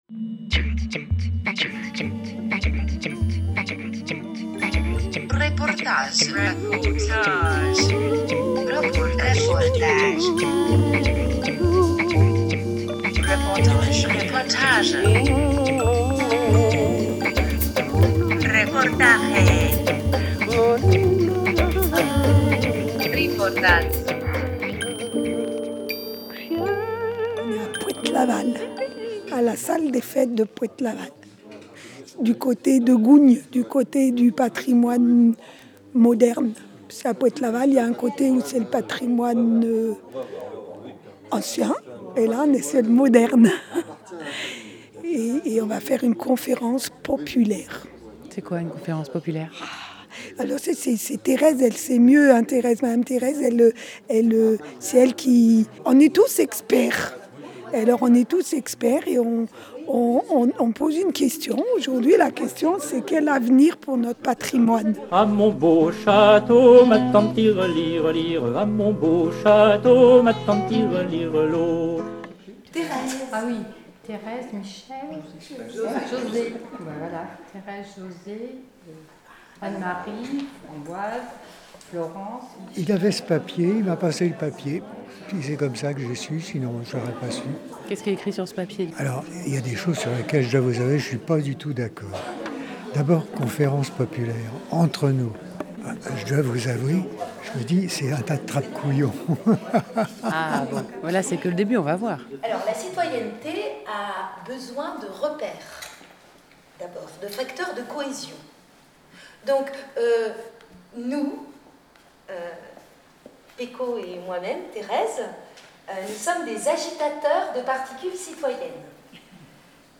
17 septembre 2016 16:29 | Interview, reportage